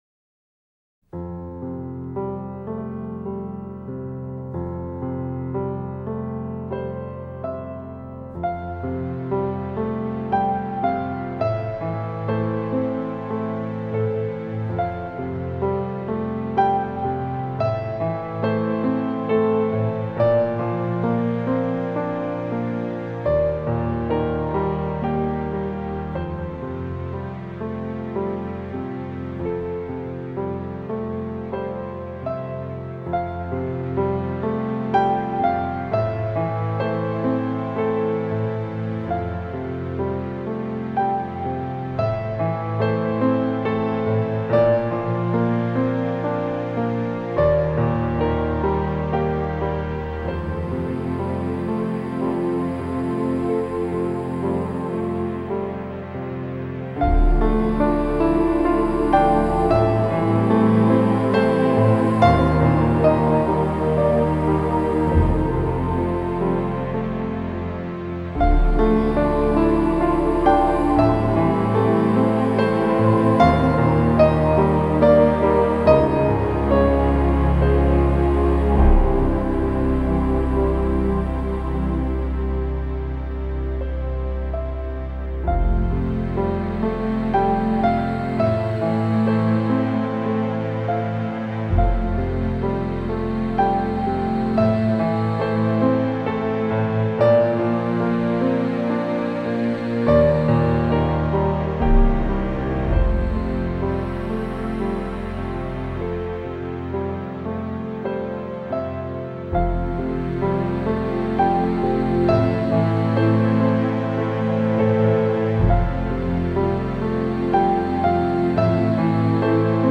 장르: Electronic
스타일: Modern Classical, Ambient